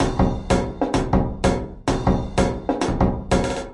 描述：奇数时间节拍120bpm
Tag: 回路 常规 时间 节奏 120BPM 节拍 敲击循环 量化 鼓环 有节奏